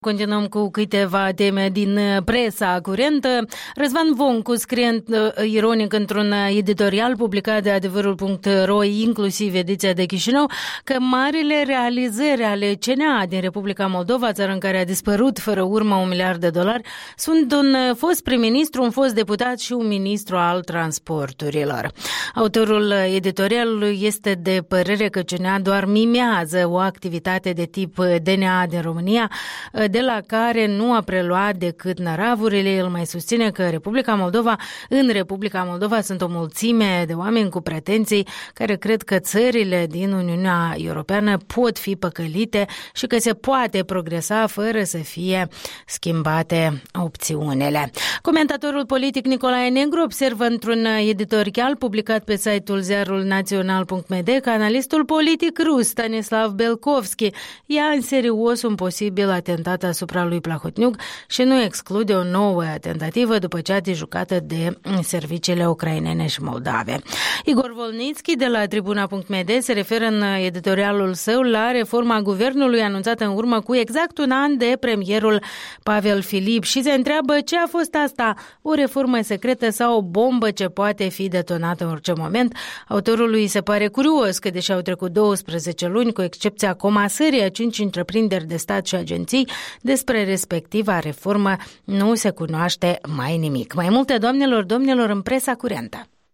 Revista presei